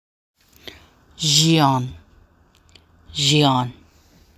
How Xian is Pronounced in The Chinatown Demons series
So how do I pronounce Xian?
003ef-xian.mp3